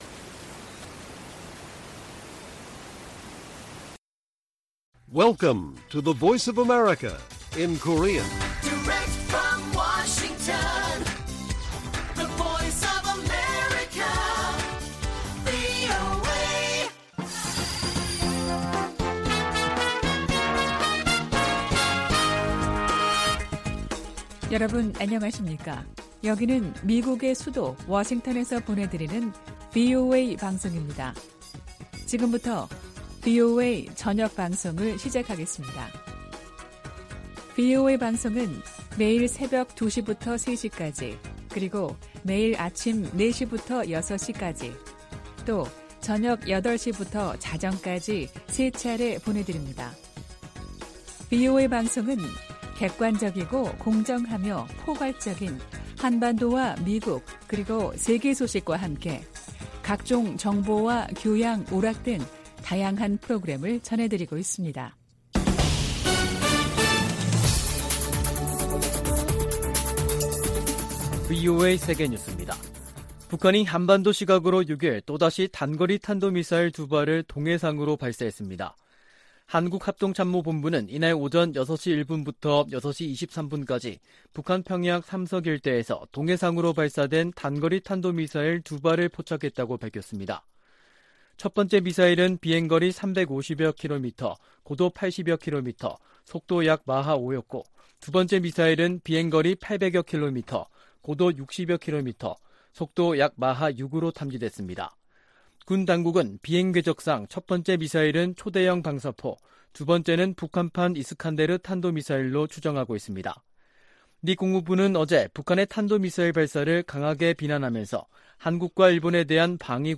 VOA 한국어 간판 뉴스 프로그램 '뉴스 투데이', 2022년 10월 6일 1부 방송입니다. 북한이 또 다시 단거리 탄도미사일 두발을 동해상으로 발사했습니다. 유엔 안보리가 북한의 탄도미사일 발사에 대응한 공개회의를 개최하고 북한을 강하게 규탄했습니다.